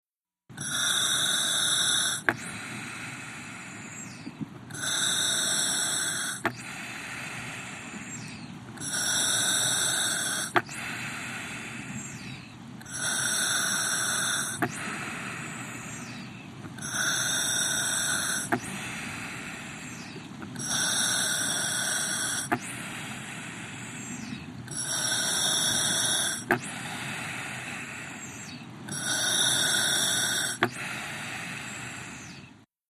WATER-UNDERWATER FX SCUBA: Regulator breathing.